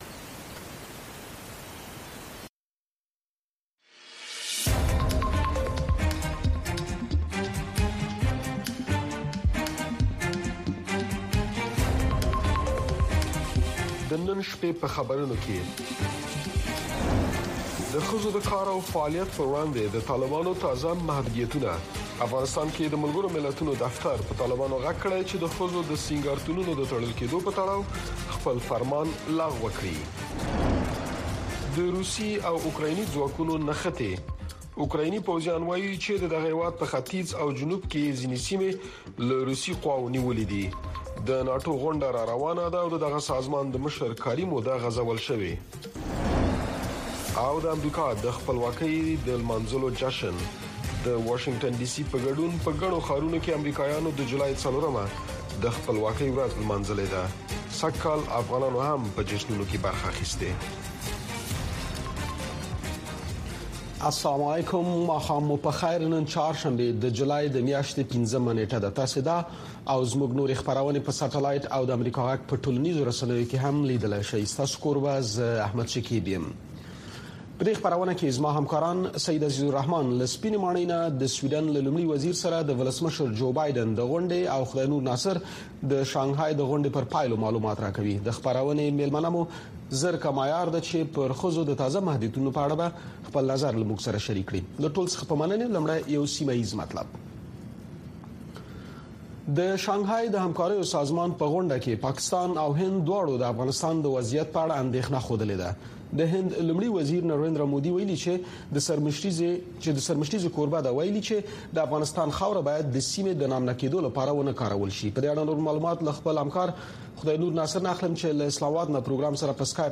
د اشنا خبري خپرونه